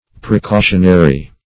precautionary - definition of precautionary - synonyms, pronunciation, spelling from Free Dictionary
Precautionary \Pre*cau"tion*a*ry\, a.